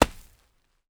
Heavy (Running)  Dirt footsteps 5.wav